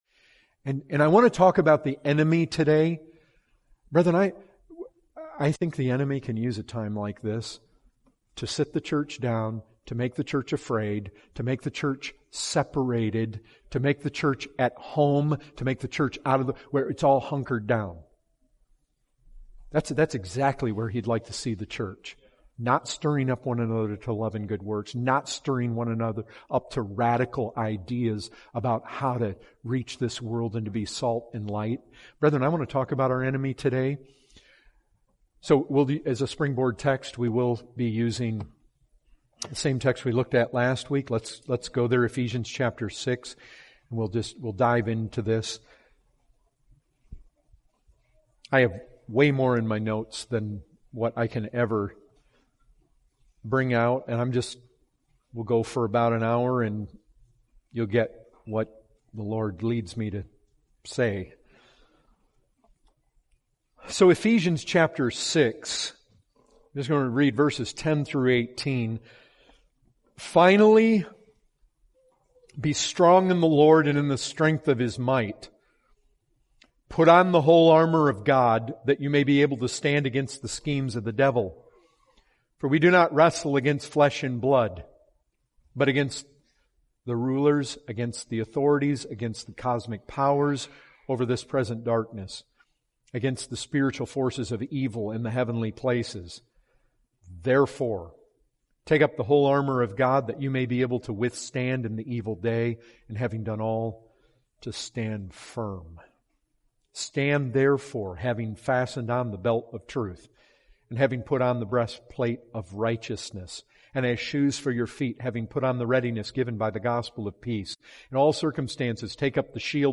2020 Category: Full Sermons The Christian is in a war